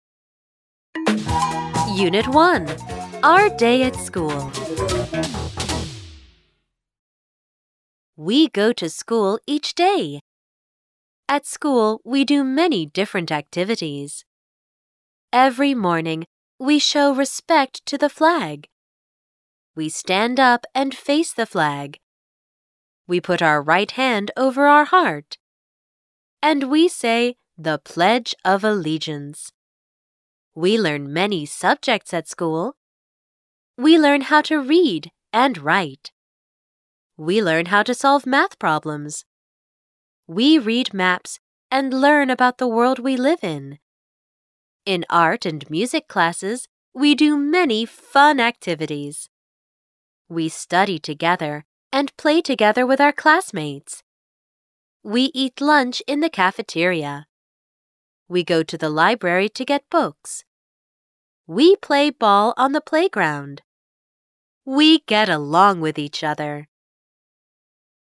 課文朗讀MP3
完整朗讀課文，一邊讀一邊背，既可增進閱讀理解，也可有效訓練英語聽力。